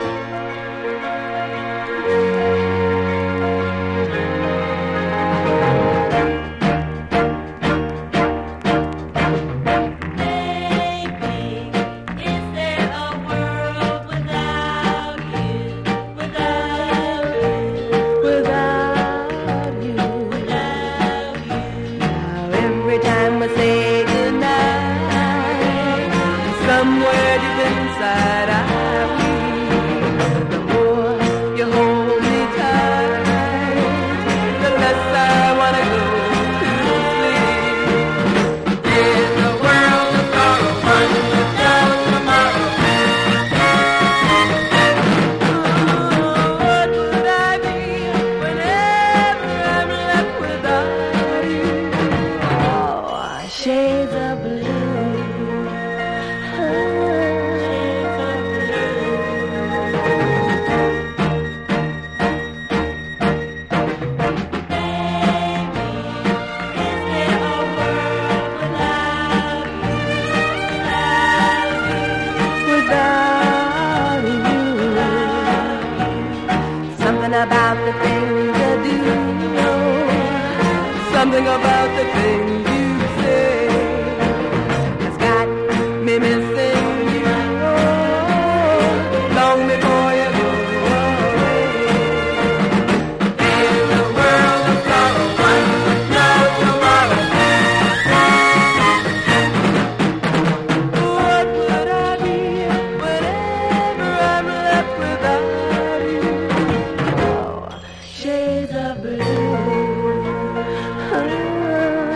きらめくサイケデリック・ポップ・ソウル